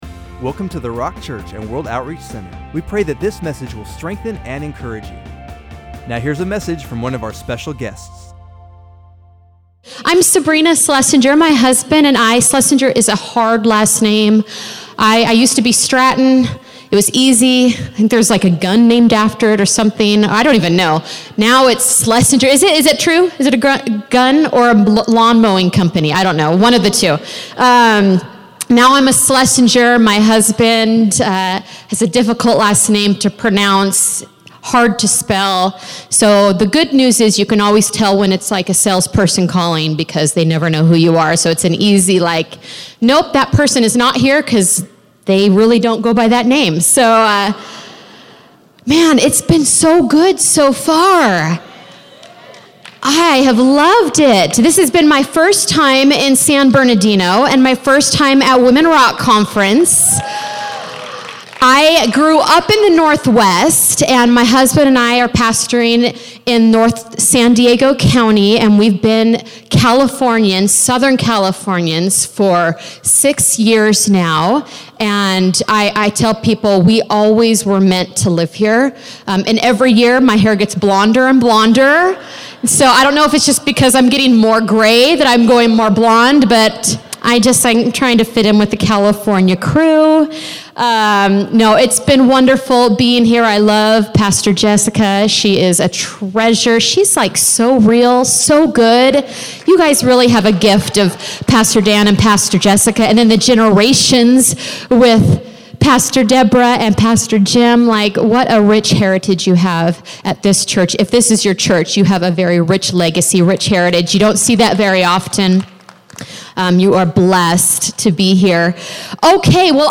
Breakout Session